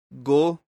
Výslovnost a pravopis